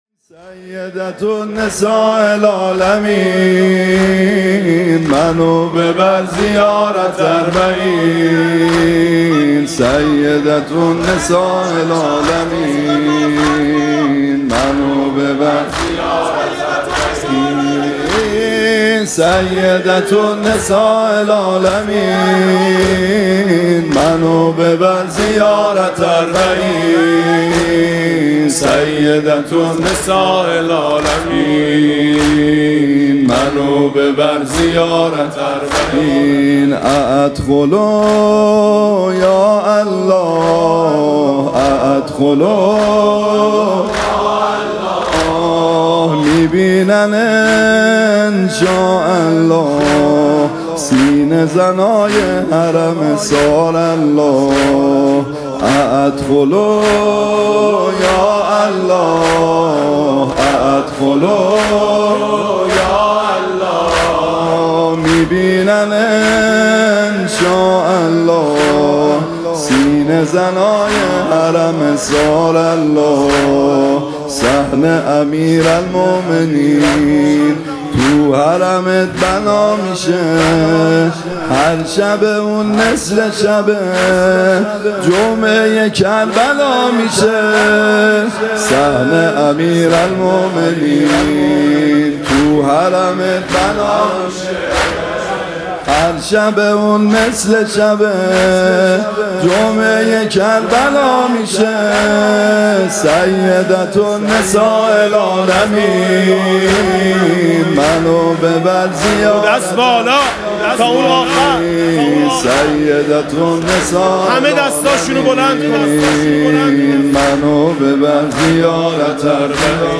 [آستان مقدس امامزاده قاضي الصابر (ع)]
عنوان: شب شهادت حضرت زهرا (س)